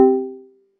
Notification Chime
A pleasant two-tone notification chime that is attention-grabbing but not intrusive
notification-chime.mp3